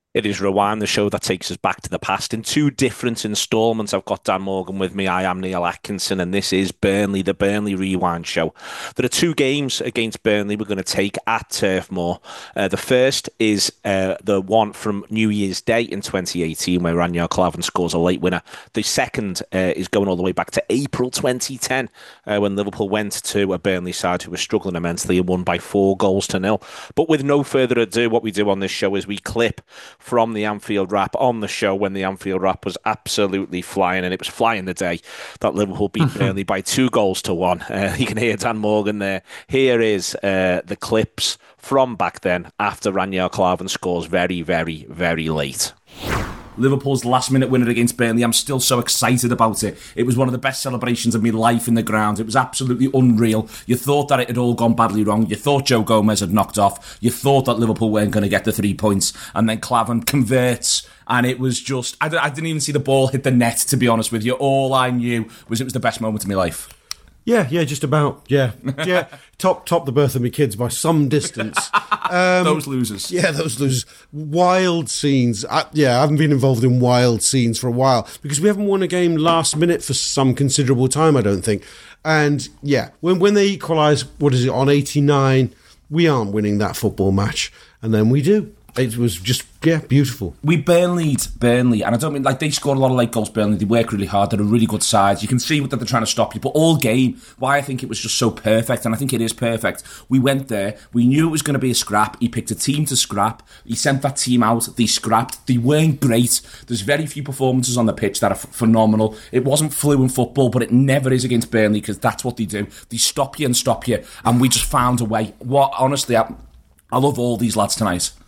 Below is a clip from the show – subscribe to The Anfield Wrap for more Liverpool chat…